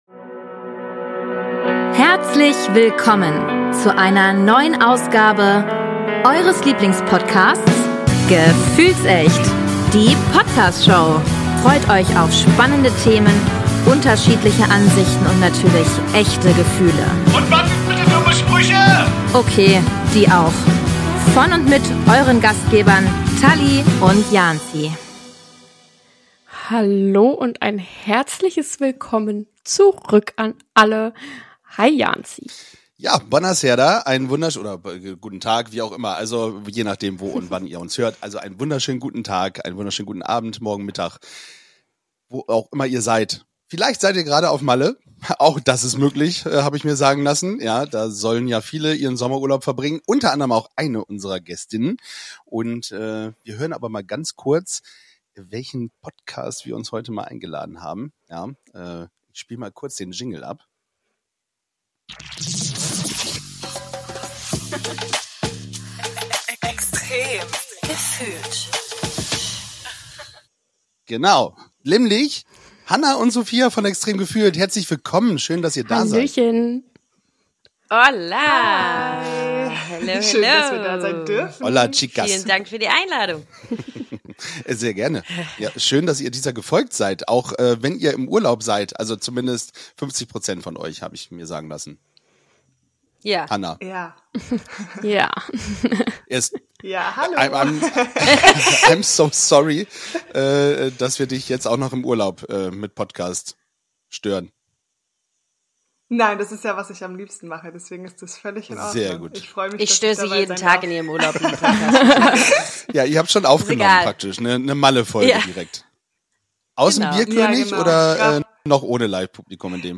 Gemeinsam tauchen sie in eine lebhafte Diskussion rund um Gefühle, Podcasting, Urlaub und spezielle Erinnerungen an Düfte und Geschmäcker. Im weiteren Verlauf der Folge geht es auch um Overthinking und mentale Gesundheit, wobei persönliche Erfahrungen und Strategien zur Bewältigung geteilt werden.